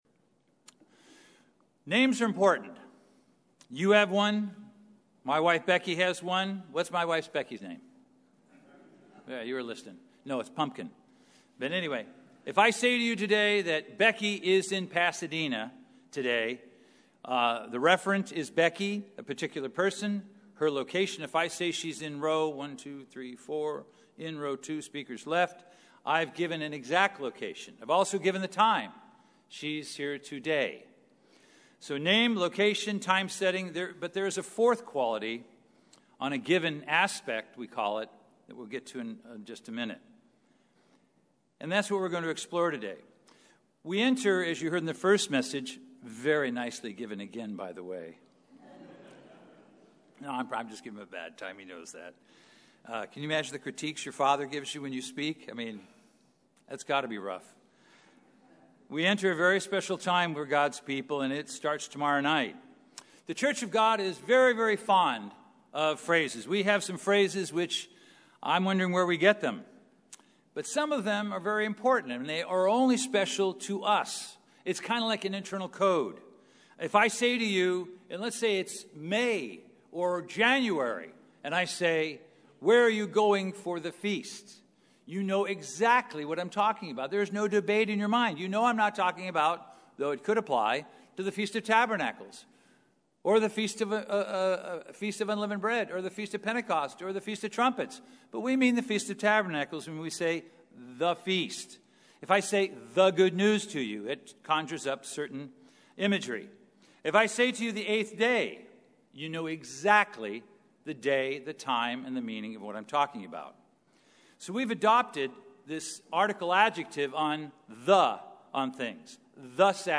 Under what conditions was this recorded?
Given in Los Angeles, CA Bakersfield, CA Las Vegas, NV Redlands, CA